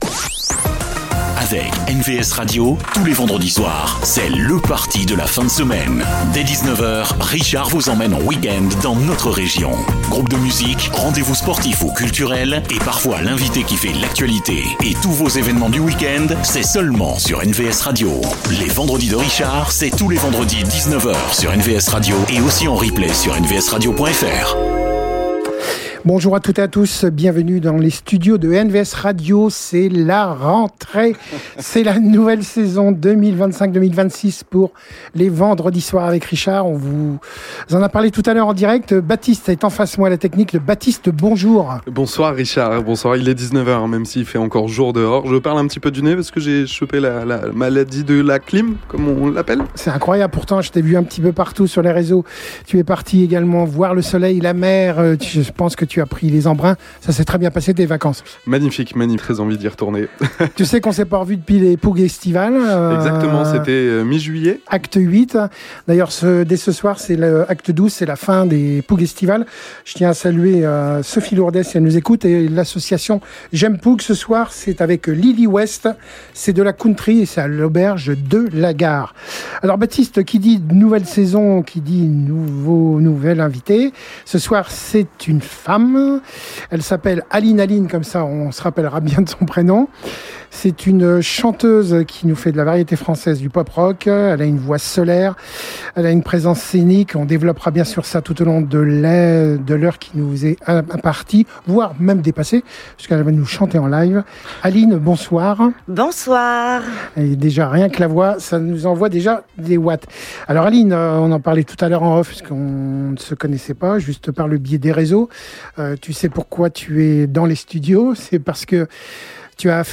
que nous avons eu le plaisir de recevoir dans nos studios ce vendredi. Une voix solaire, un univers pop-rock aux couleurs françaises, anglaises et espagnoles…